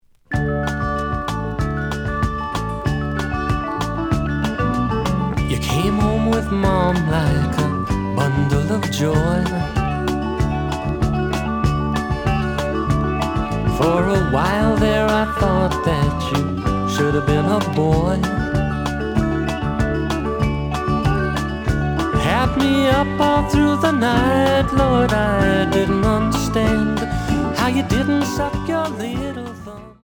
The audio sample is recorded from the actual item.
●Genre: Folk / Country